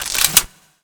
sci-fi_weapon_reload_05.wav